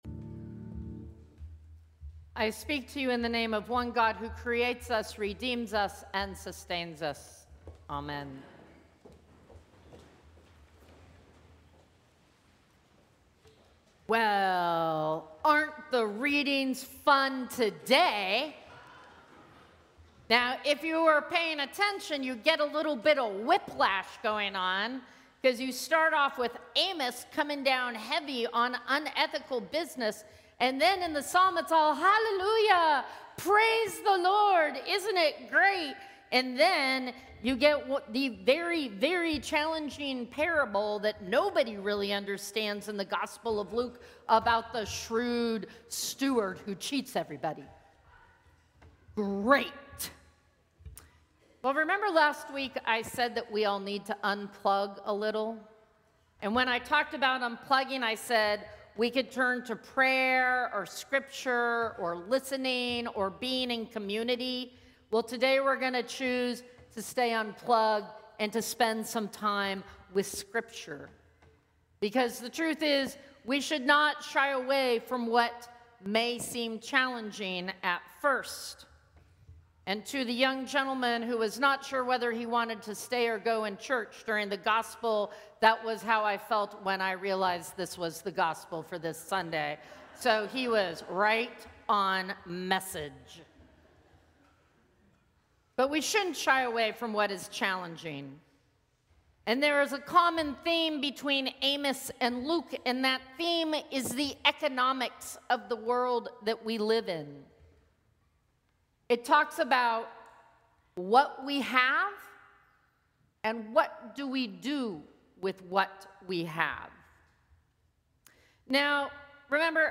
Sermons from St. Cross Episcopal Church Fifteenth Sunday after Pentecost Sep 21 2025 | 00:12:29 1x 00:00 / 00:12:29 Subscribe Share Apple Podcasts Spotify Overcast RSS Feed Share Link Embed